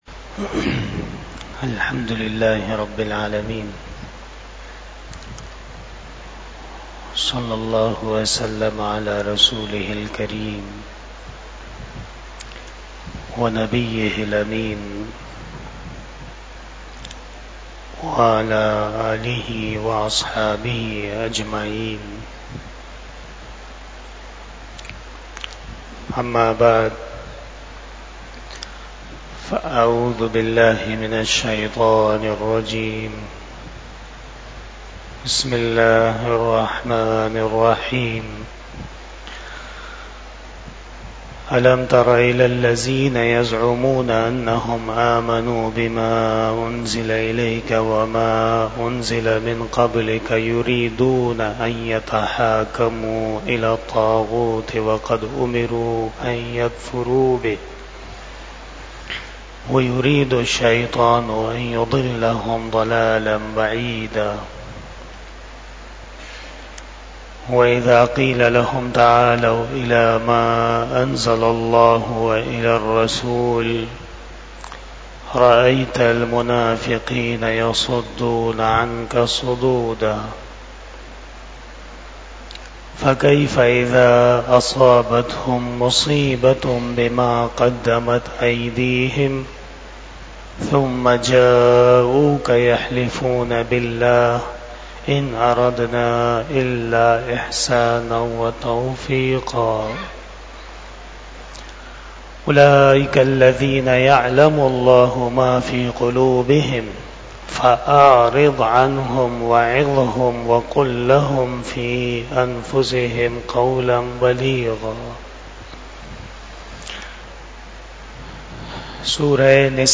25 Shab E Jummah Bayan 01 August 2024 (25 Muharram 1446 HJ)